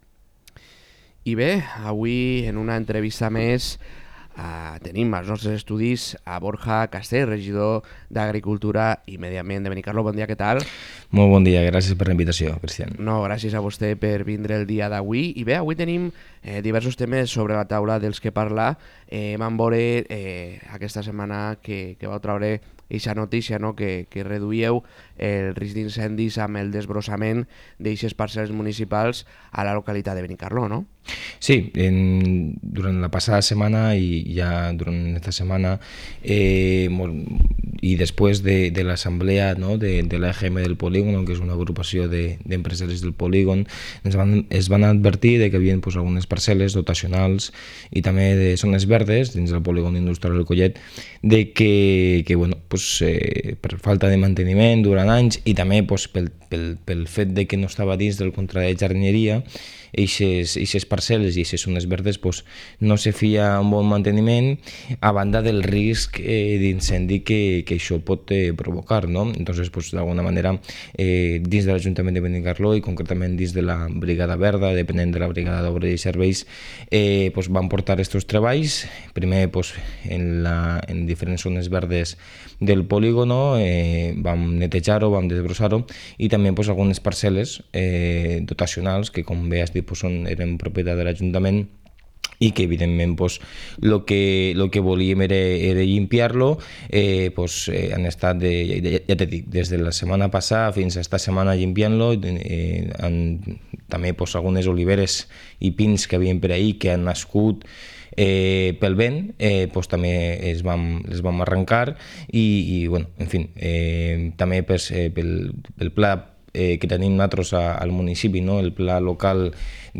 Podcast | Entrevista a Borja Castell regidor d'Agricultura i Medi ambient a Benicarló